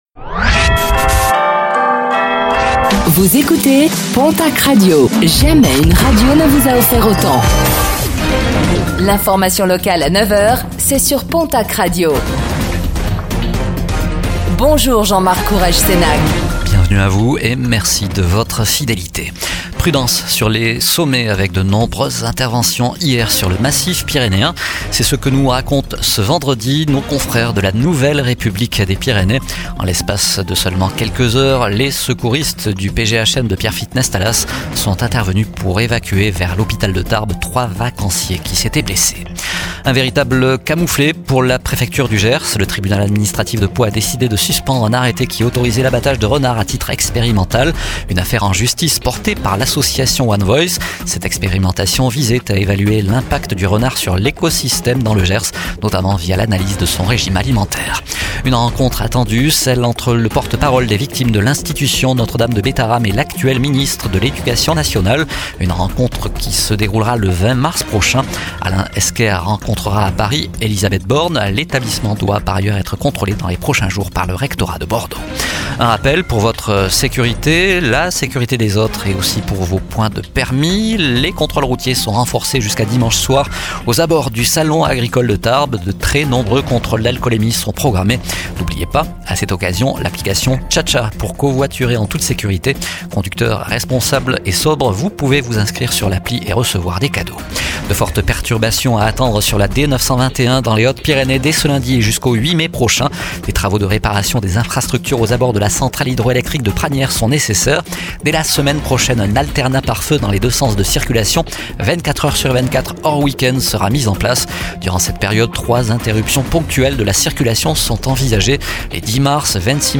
Infos | Vendredi 07 mars 2025